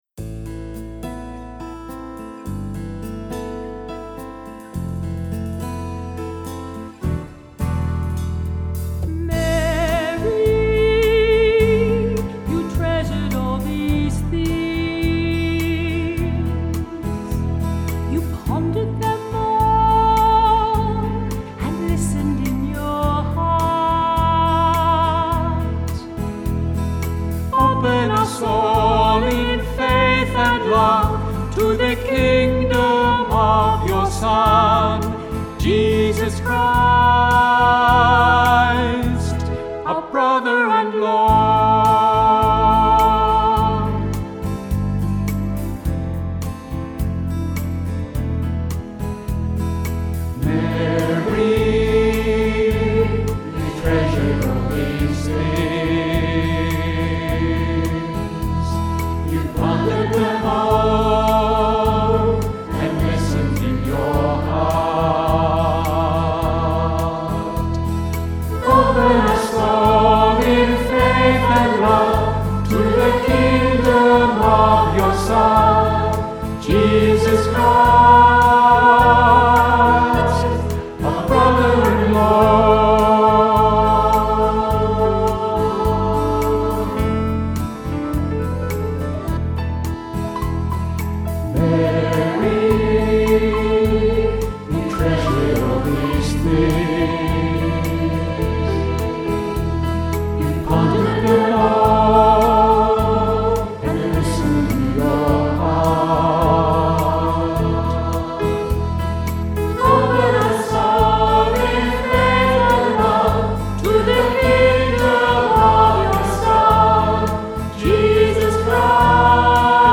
a chant in a slightly jazz style